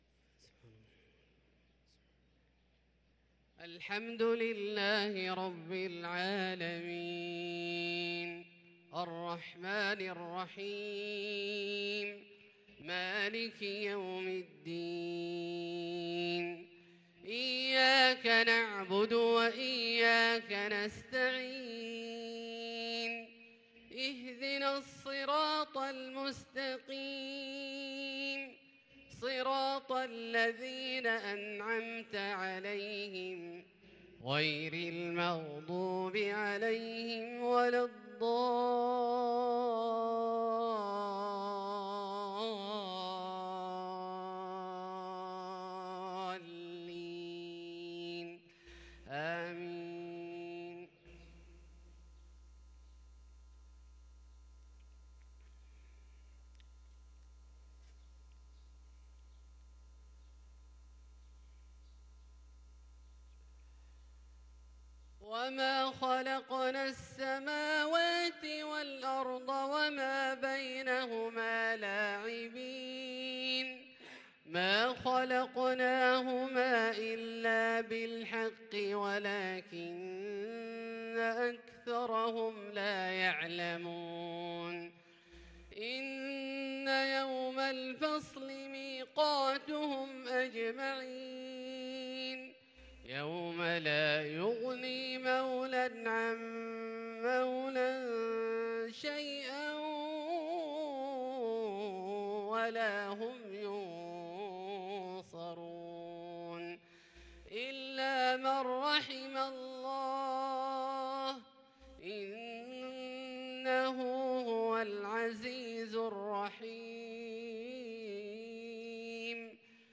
صلاة المغرب للقارئ عبدالله الجهني 13 جمادي الآخر 1444 هـ